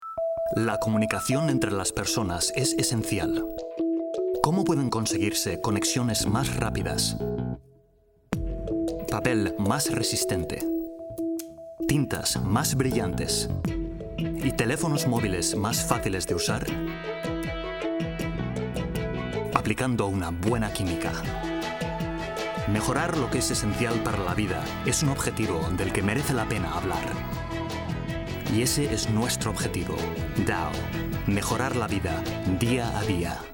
kastilisch
Sprechprobe: Werbung (Muttersprache):
Native Castilian Spanish actor with over 10 years of professional voiceover credits in TV and radio ads, audioguides, corporate films, videogames, eLearning and language courses.